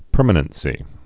(pûrmə-nən-sē)